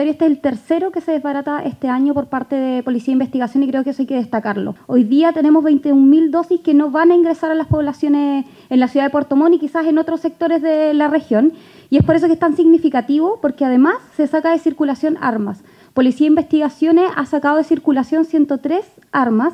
En tanto, la delegada Presidencial Regional, Giovanna Moreira, destacó el trabajo de las policías, agregando que este es el tercer desbaratamiento que se ha realizado en la zona.
delegada-decomiso.mp3